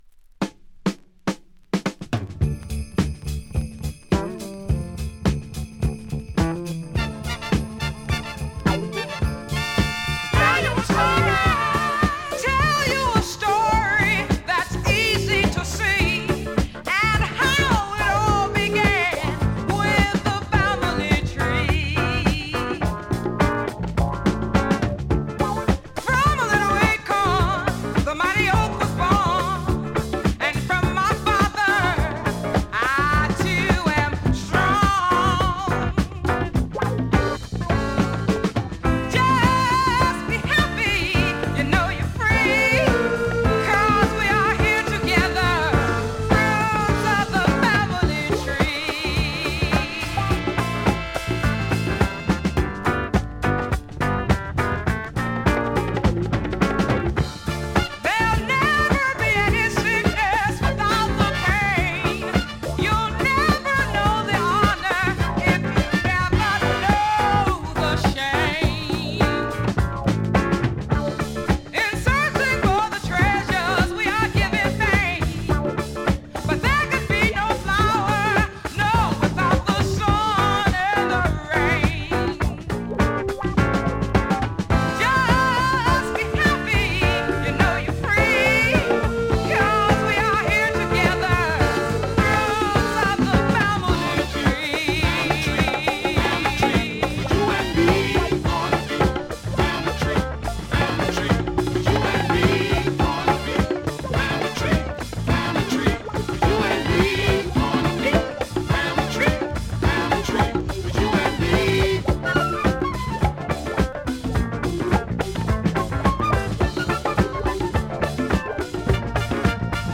現物の試聴（両面すべて録音時間６分）できます。